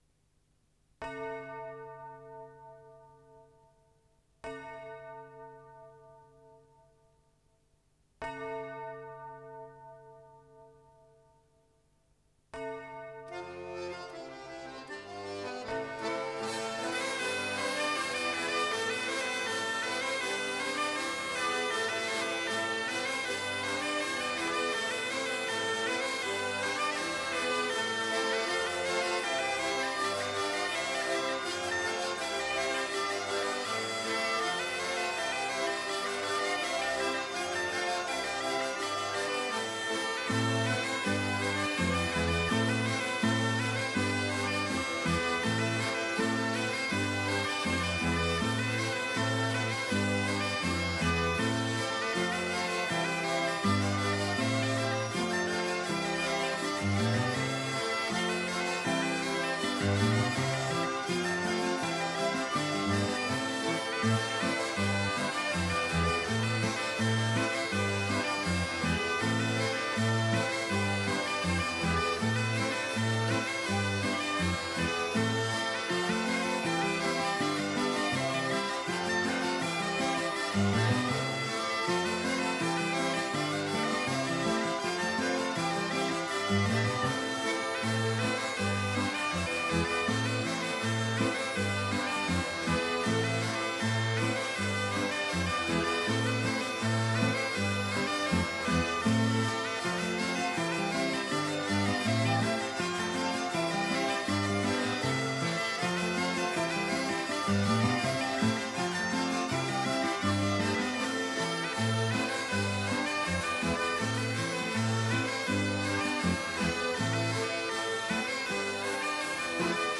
enregistré à Seurre en décembre 1991